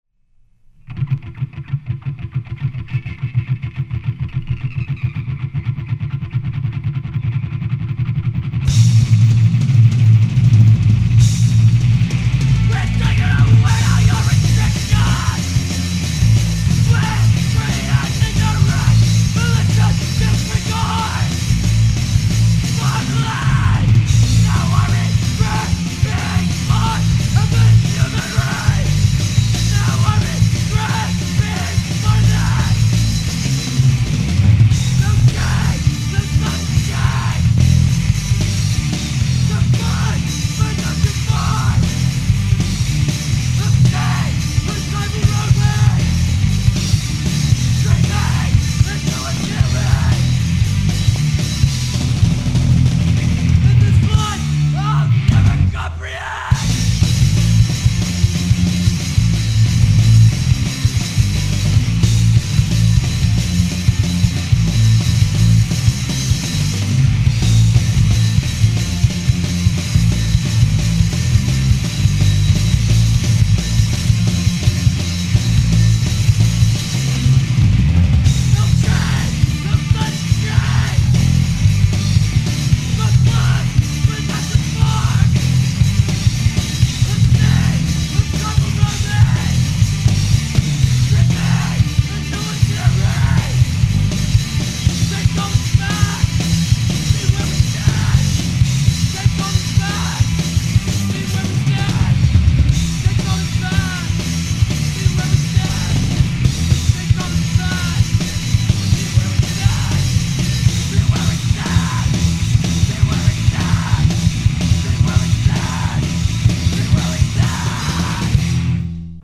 I was the singer and guitar player in this band.